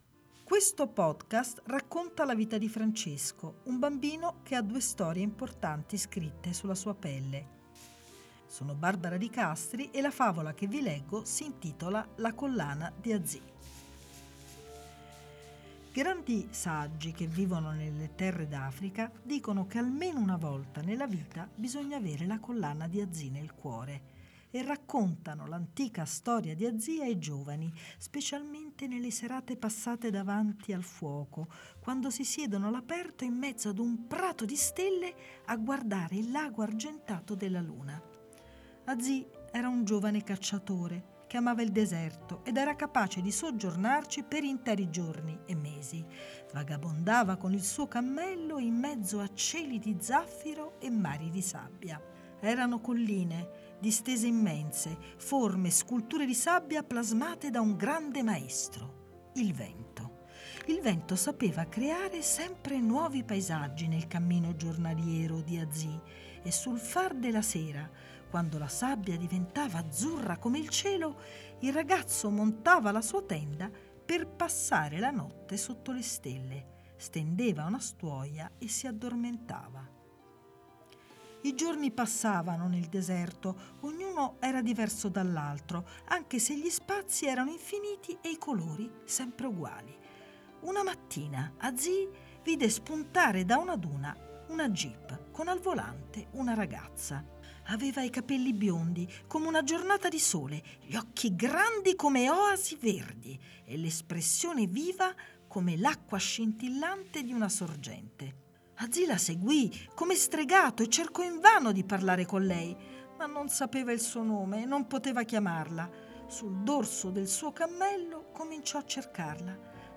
Music under courtesy of Forte Media & Consulting Sagl